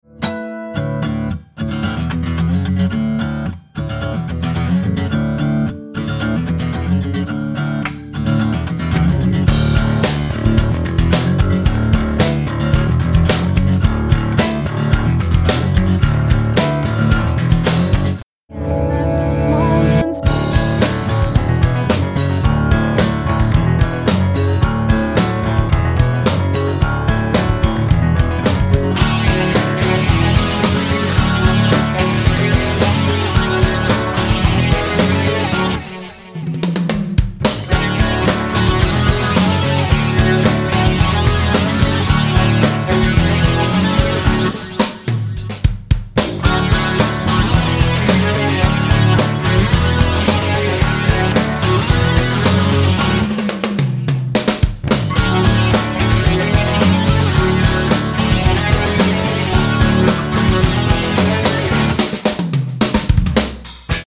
drum breaks